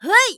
YX发力2.wav 0:00.00 0:00.39 YX发力2.wav WAV · 33 KB · 單聲道 (1ch) 下载文件 本站所有音效均采用 CC0 授权 ，可免费用于商业与个人项目，无需署名。
人声采集素材